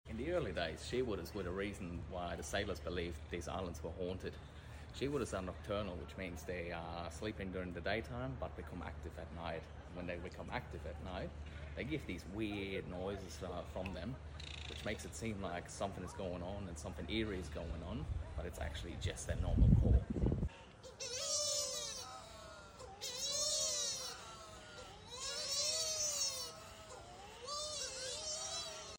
Wait until the end to hear them… they sound like crying babies in the night 😳 The sounds of the Shearwater, a seabird on the Great Barrier Reef.